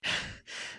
breath3.ogg